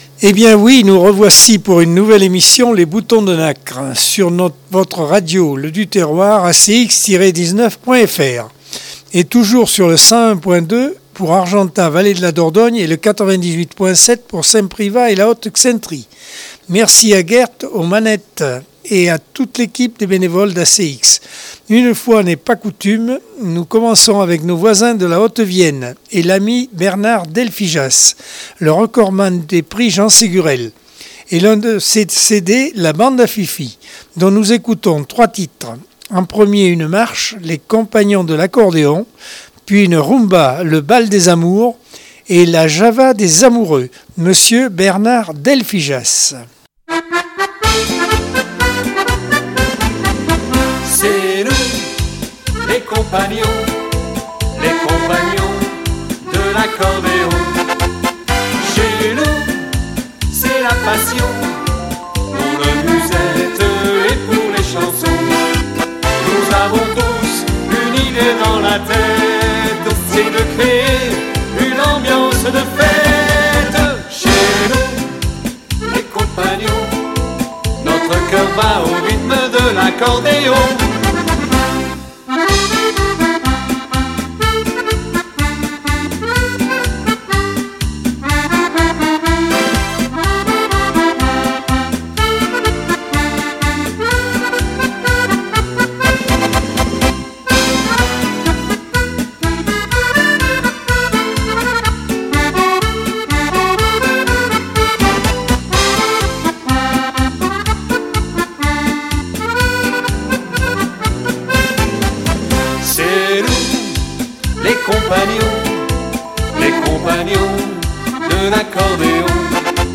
Accordeon 2022 sem 45 bloc 1 - Radio ACX